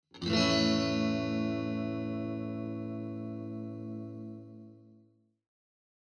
Clean Sound Guitar
Cleanジャラーン(A) 118.27 KB